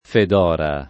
Fëdor [russo fL0dër] o Feodor [fi-0dër] pers. m. (= Teodoro); f. Feodora [fiad0] — tedeschizz. Fedor [f%edoo9]; f. Fedora [fed1ora] — italianizz., nel femm., in Fedora [